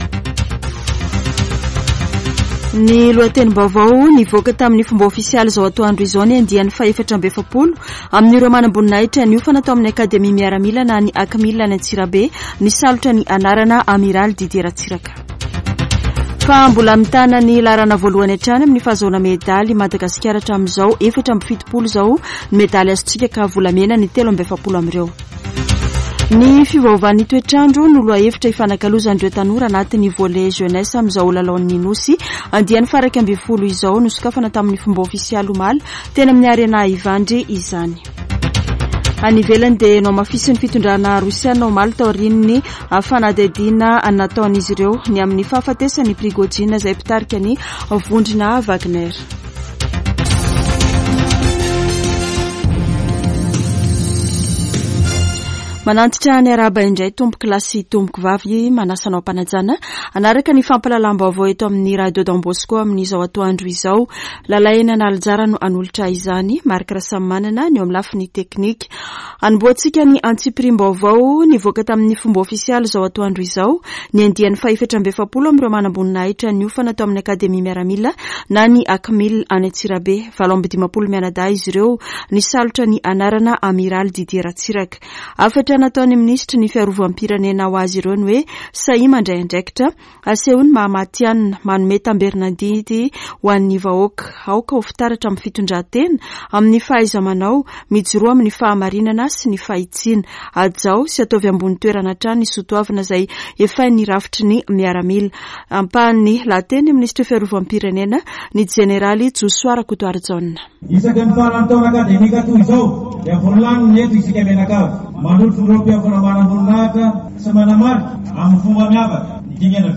[Vaovao antoandro] Alatsinainy 28 aogositra 2023